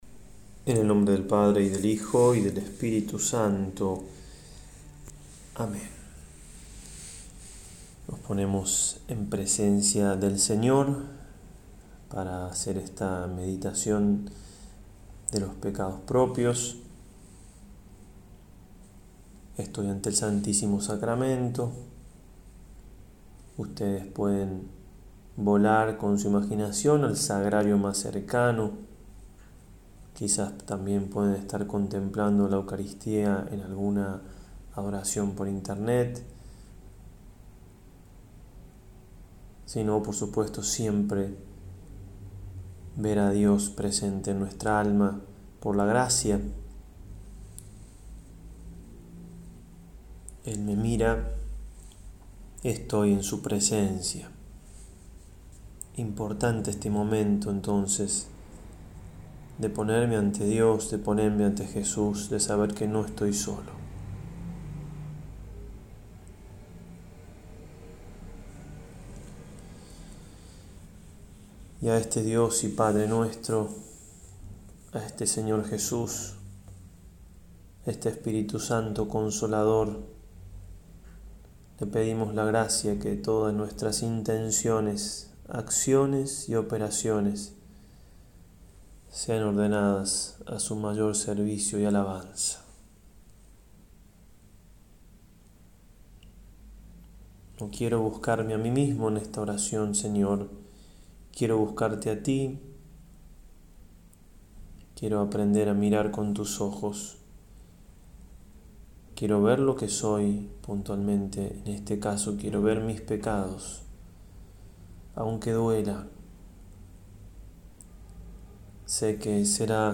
09-Extra-Meditacion-Guiada-Pecados-Propios.mp3